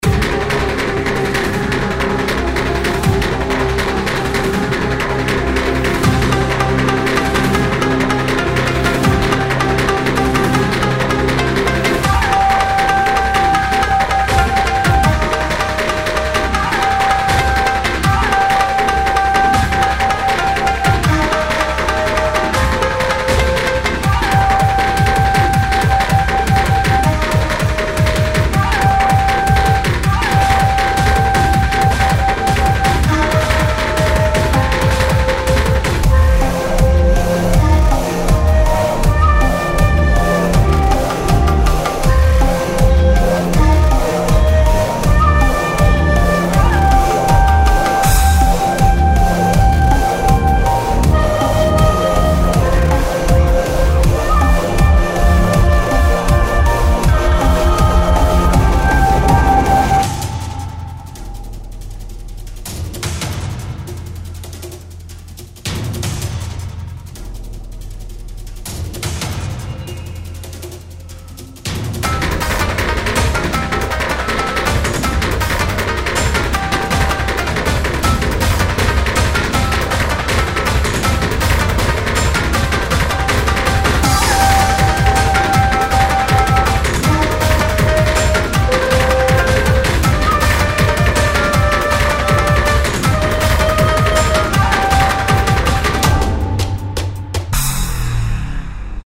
和風テイストの旋律にデジタルビートを融合させたバトルBGMです。
• BPM160、アップテンポの中でも重厚感を演出
• ダークなシンセパッドと攻撃的なパーカッションが土台
• 尺八・三味線がメロディラインを担当
• ブレイクビーツ調のリズムでサイバー感を注入
静寂と激しさが共存する和風戦闘BGMです。
ロック
サイバーパンク